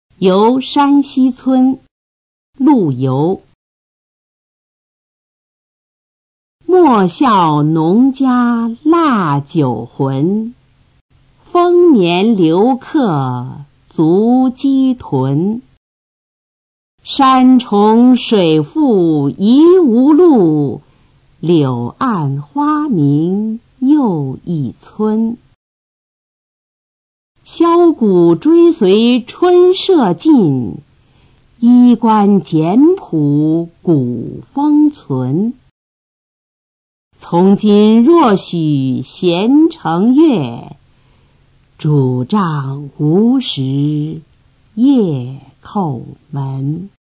《游山西村》原文和译文（含赏析、朗读）　/ 佚名